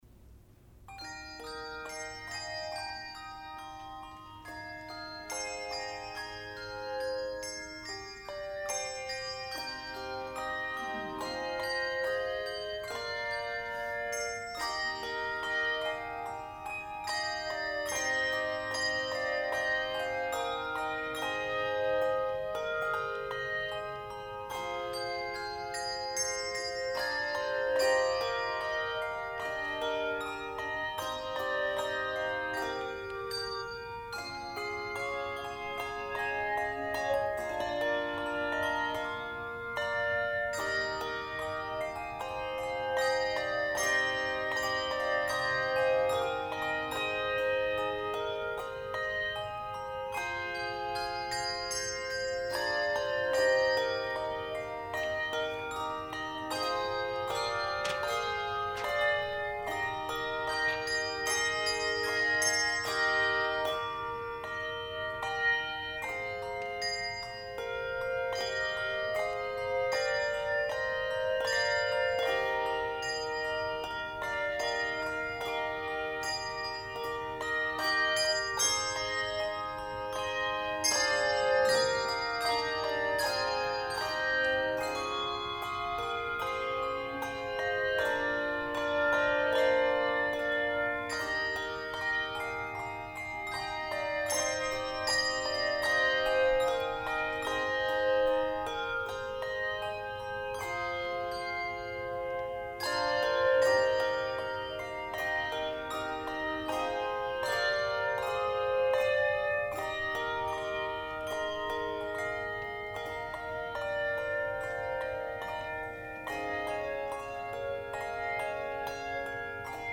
What a Friend We Have in Jesus, Handbell Choir
Performer:  Handbell Choir